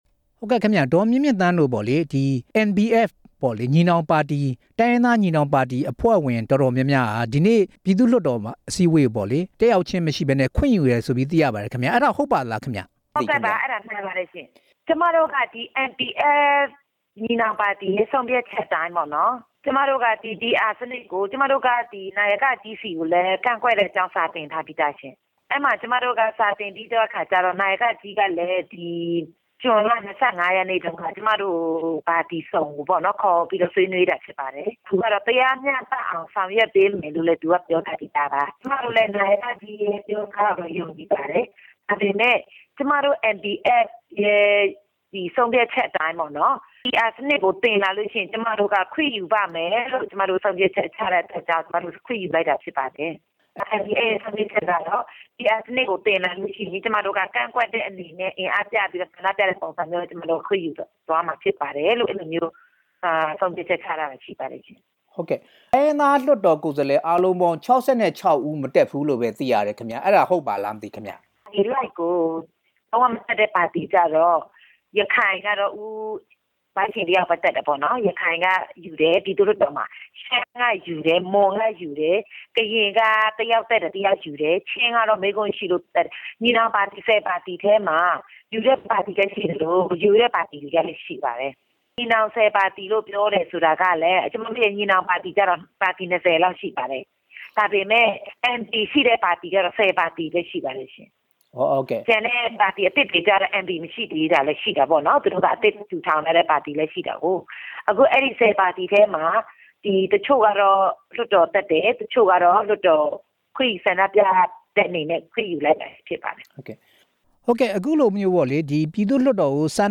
လွှတ်တော်မှာ PR စနစ်ကို ဆွေးနွေးမယ်ဆိုရင် ခွင့်ယူဖို့ NBF အစည်းအဝေးက ဆုံးဖြတ်ချက်ချ ထားပြီးဖြစ် တယ်လို့ ဆိုပါတယ်။ NBF အဖွဲ့ဝင် မွန်ဒေသလုံးဆိုင်ရာ ဒီမိုကရေစီပါတီက ပြည်သူ့ လွှတ်တော်ကိုယ်စား လှယ် ဒေါ်မြင့်မြင့်သန်းက အခုလိုပြောပါတယ်။